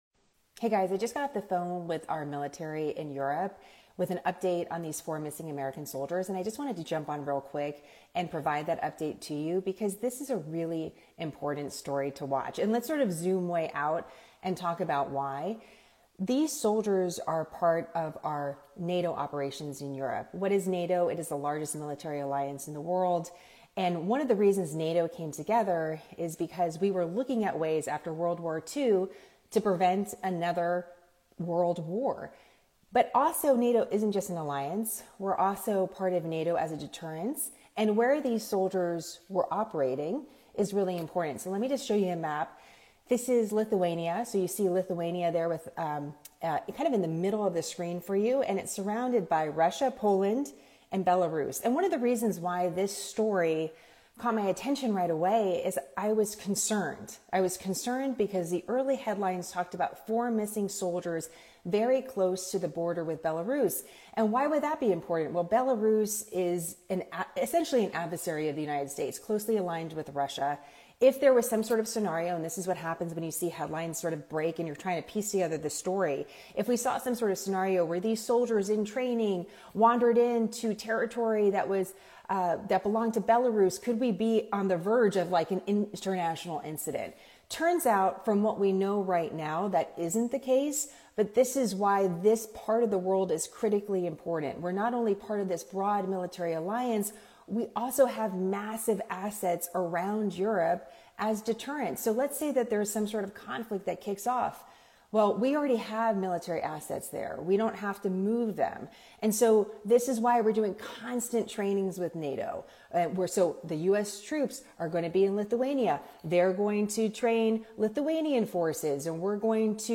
Recorded on an Instagram LIVE for speec.